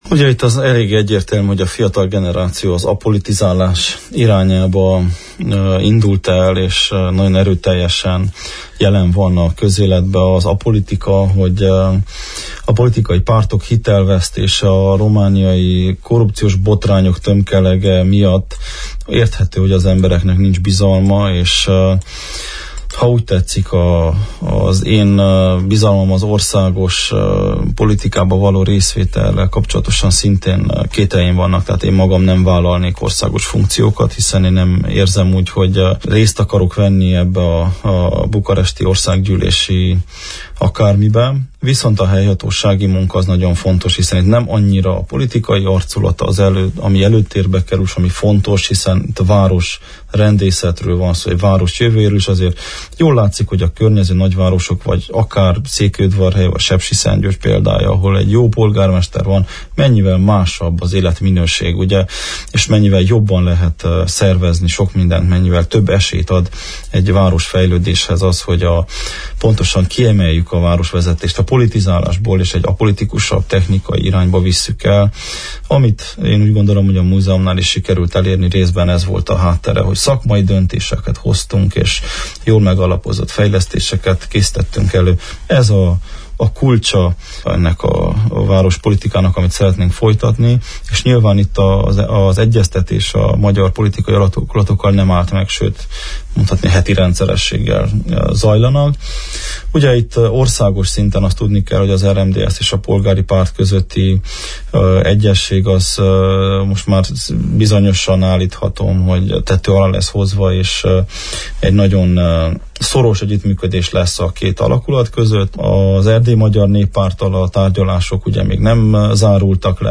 a következőket nyilatkozta a kedd délután elhangzott Naprakész műsorban